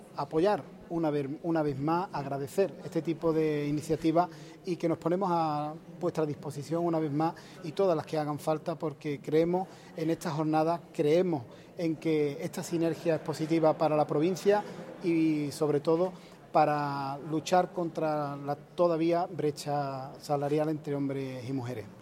El diputado participa en la inauguración de una jornada organizada por Mujeres Imparables, financiada por la administración provincial